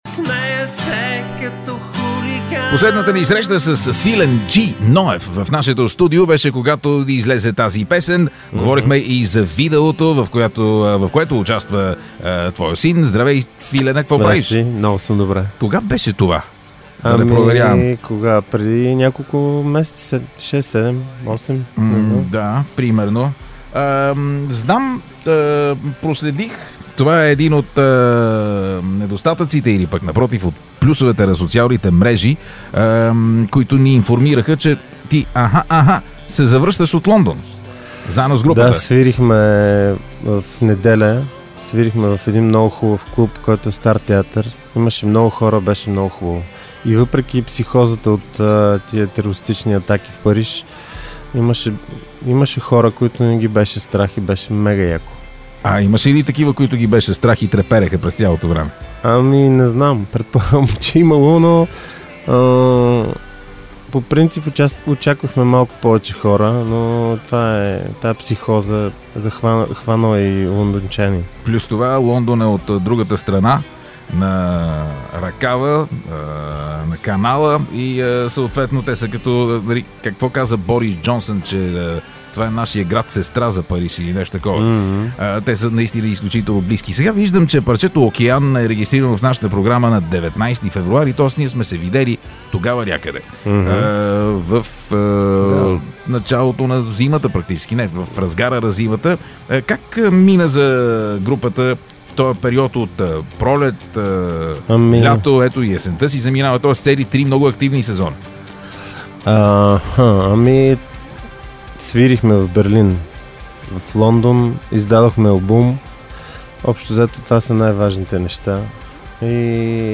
едно интервю